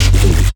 Key-bass_68.2.4.wav